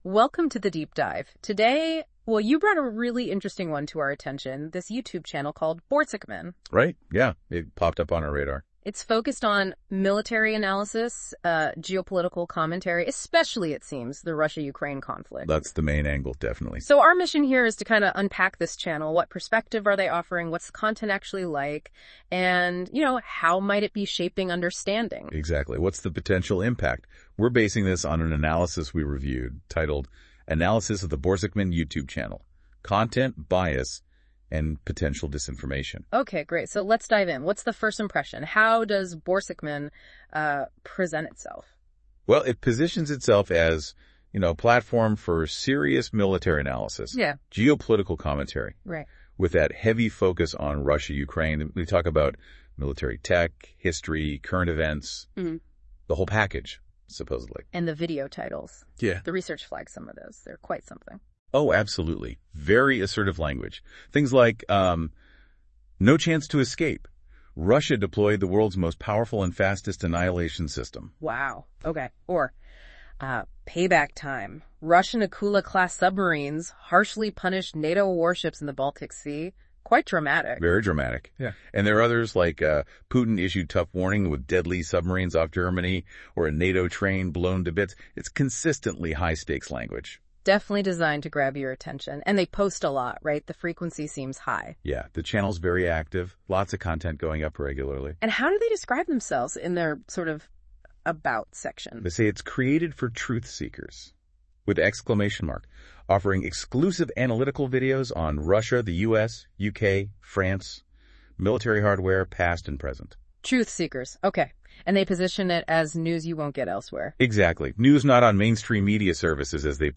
This kind of AI generated "deep dive" discussion first sounds quite impressive but after a while you start noticing weirdness.